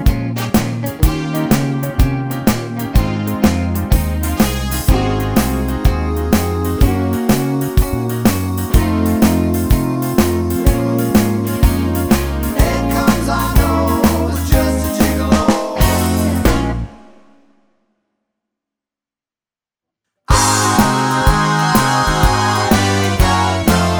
no Backing Vocals Jazz